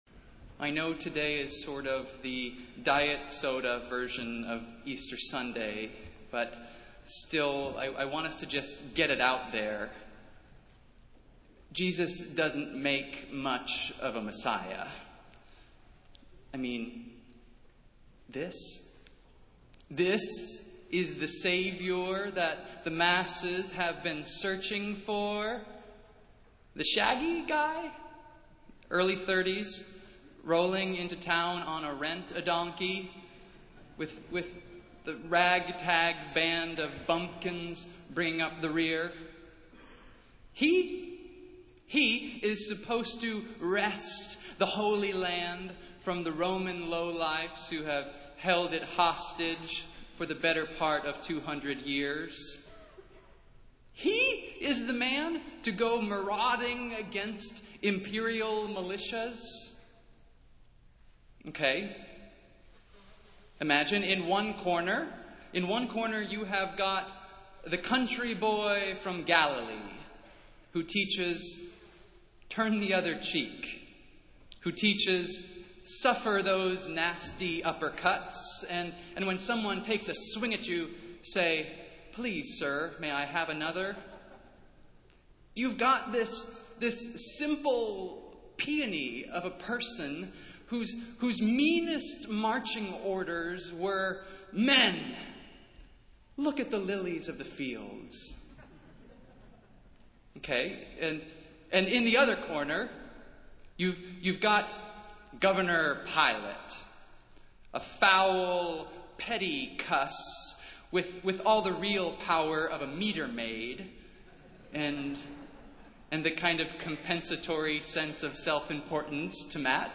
Festival Worship - Palm Sunday